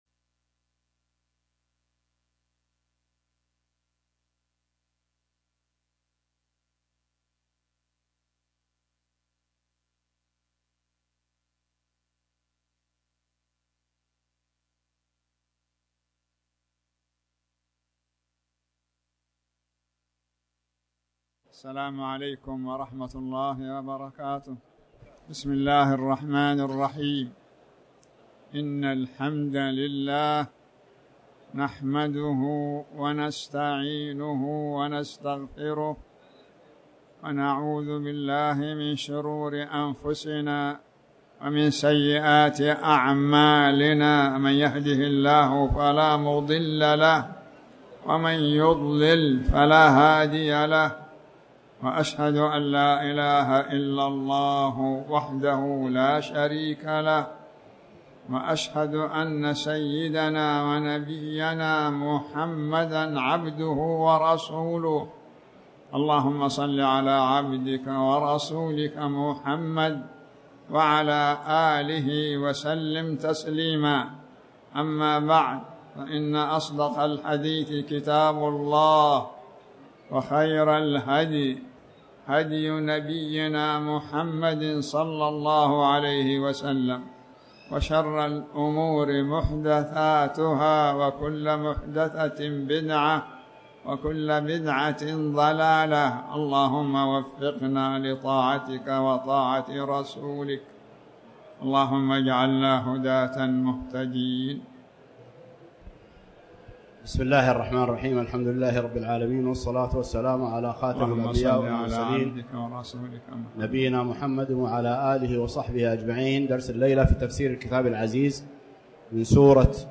تاريخ النشر ٣ ذو القعدة ١٤٣٩ هـ المكان: المسجد الحرام الشيخ